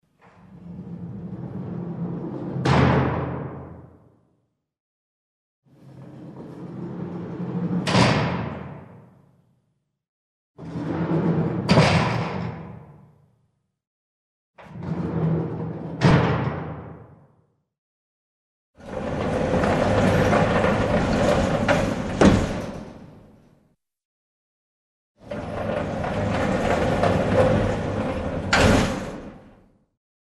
Тюремная решетка открывается, закрывается
• Категория: Тюрьма, кологния
• Качество: Высокое
На этой странице вы можете прослушать звук тюремная решетка открывается, закрывается.